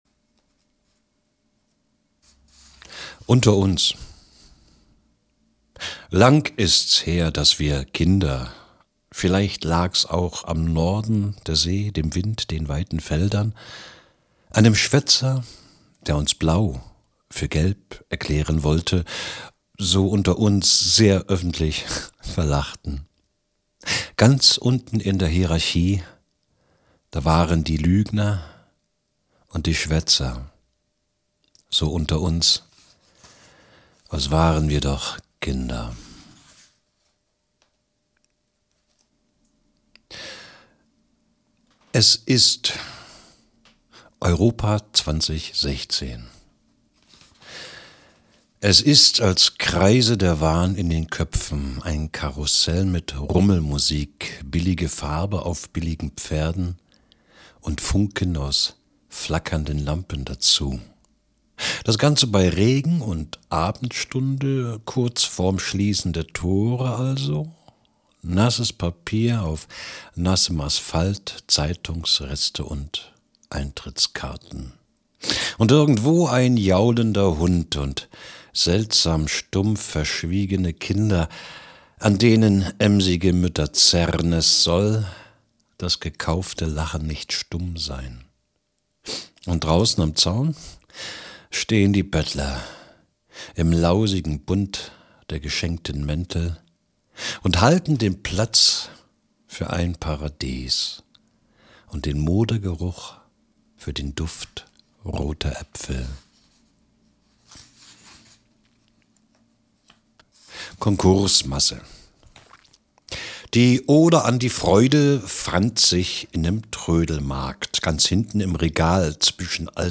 lesung teil 2
lesung-teil-2.mp3